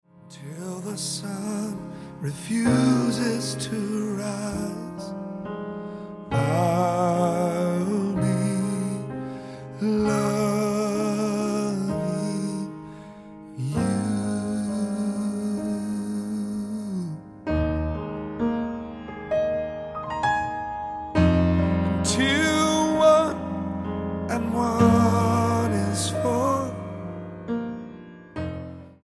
STYLE: Gospel
grand piano, the mood is downbeat throughout